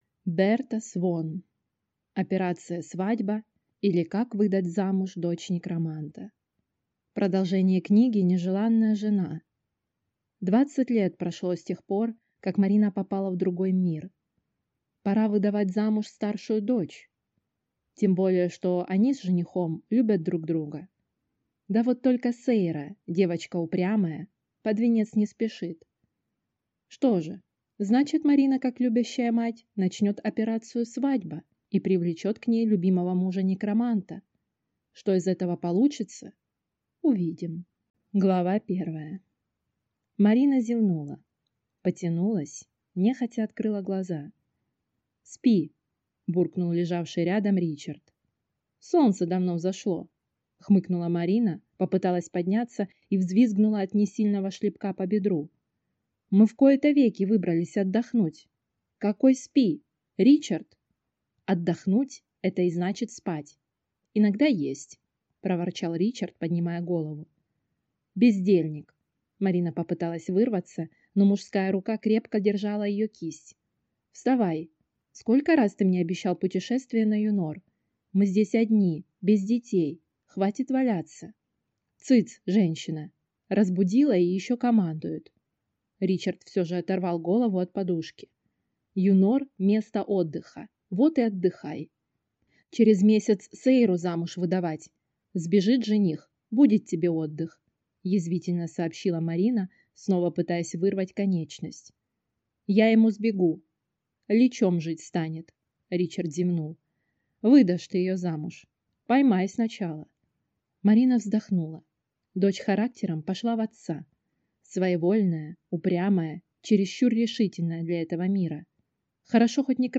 Аудиокнига Операция свадьба, или Как выдать замуж дочь некроманта | Библиотека аудиокниг